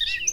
warbler.005.wav